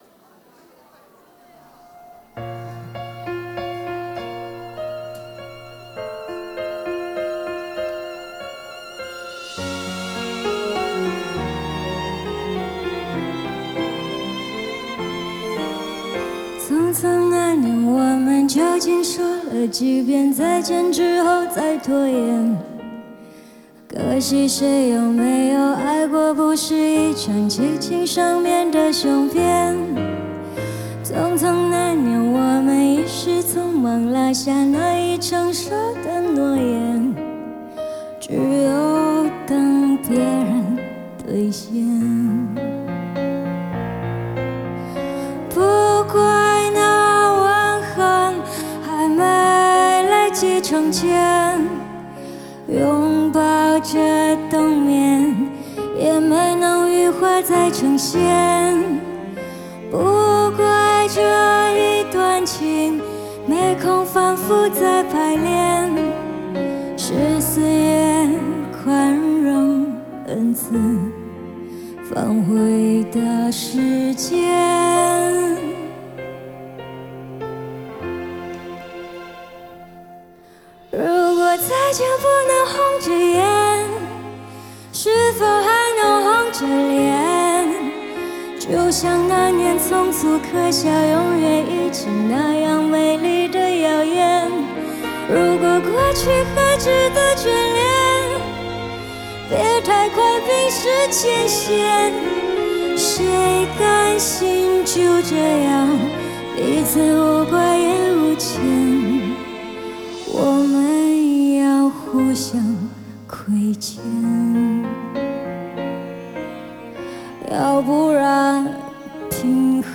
Ps：在线试听为压缩音质节选，体验无损音质请下载完整版
键盘
吉他
贝斯
鼓